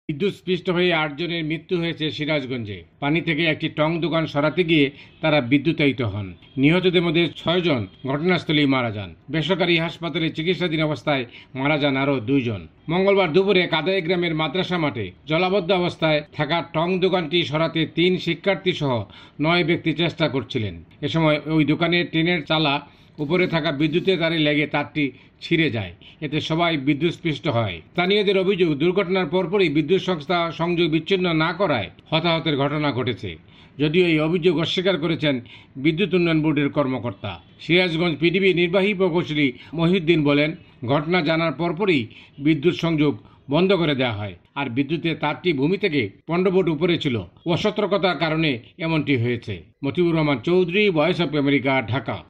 Dhaka, Bangladesh